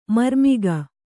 ♪ marmiga